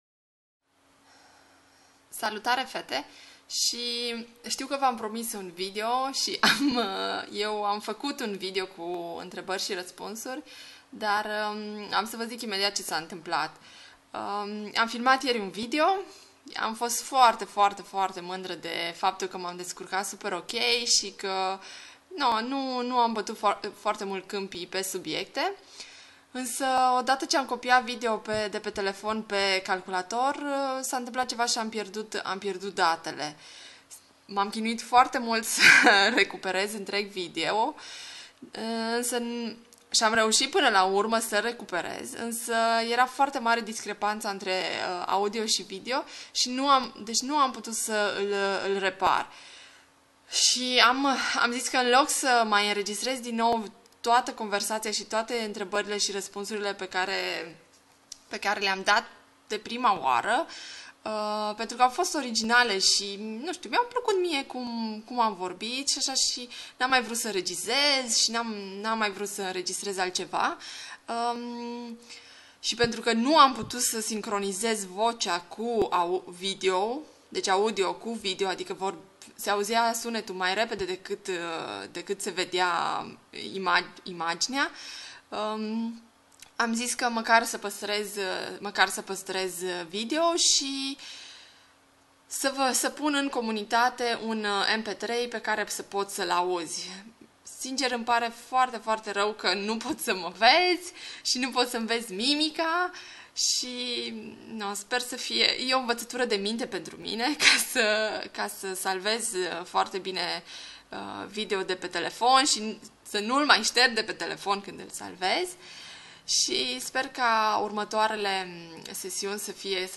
Prima sesiune de intrebari si raspunsuri, doar audio de data aceata, din comunitatea Femeia Fit.